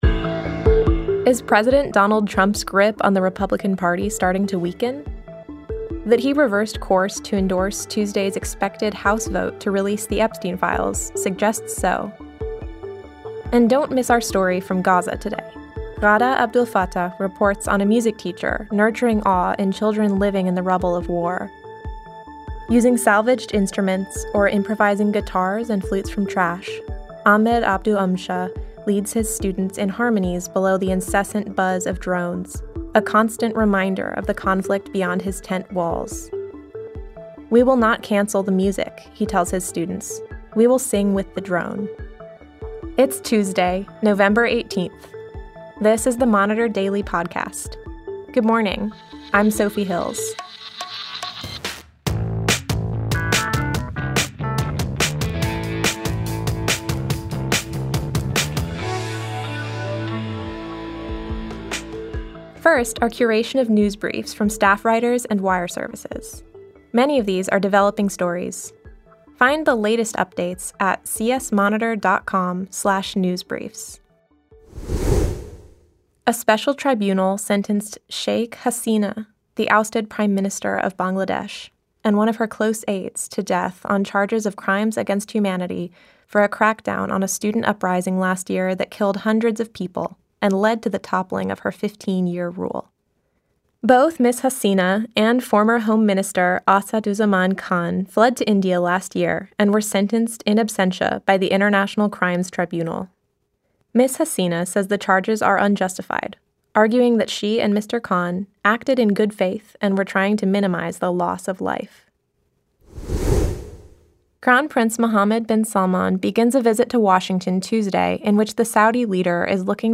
The editors of The Christian Science Monitor take you beyond the headlines with the ideas driving progress in this 15-minute news briefing.